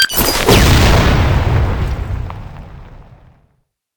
grenadethrow.ogg